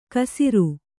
♪ kasiru